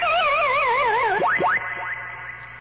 PacmanDies.wav